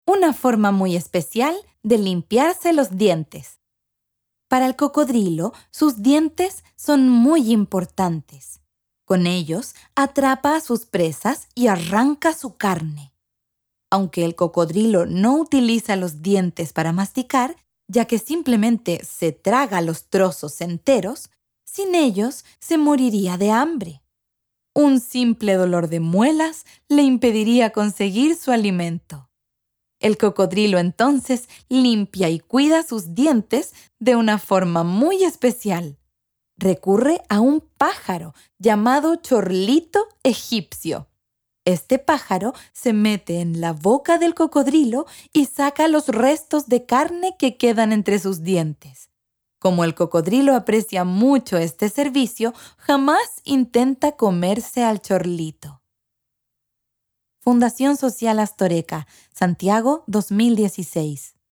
Audiocuento
Audiocuento informativo sobre cómo el cocodrilo se limpia los dientes gracias a la ayuda del pájaro chorlito, una relación simbiótica en la que el pájaro se alimenta de los restos de comida entre los dientes del cocodrilo, mientras que el reptil recibe una limpieza dental natural y beneficiosa.